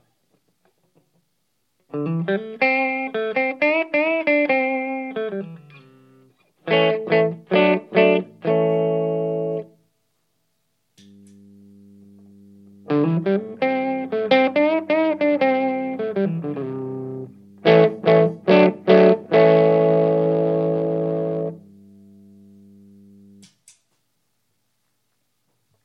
Ich habe gerade einen da und bin ziemlich erschrocken, wie laut das Ding brummt! Nur Gitarre -> Charlie -> Amp. Volume am Charlie ist auf ca. 12 Uhr, Drive ist auf Minimum, am EQ ist alles auf 12 Uhr.
Hier eine kleine Aufnahme, zuerst ohne Angry Charly, dann eingeschaltet mit den oben genannten Einstellungen: Ist das Ding kaputt oder ist das normal?